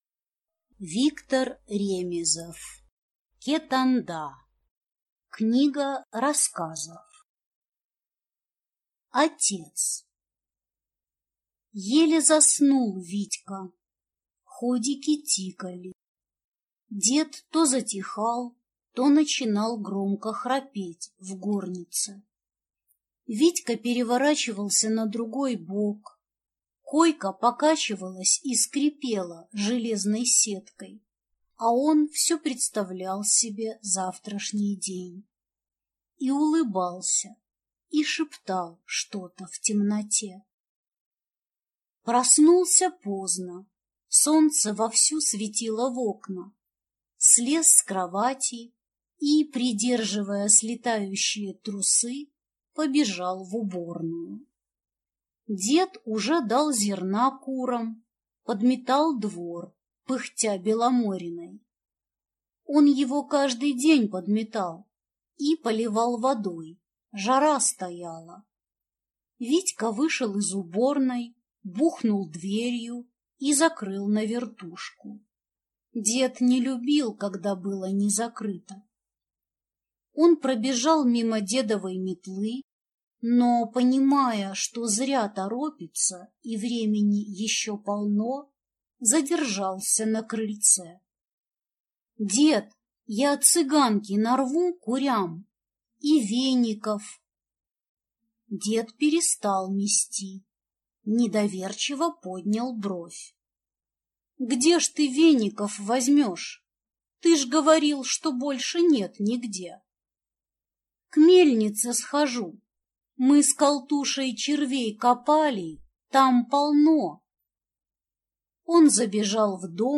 Аудиокнига Кетанда | Библиотека аудиокниг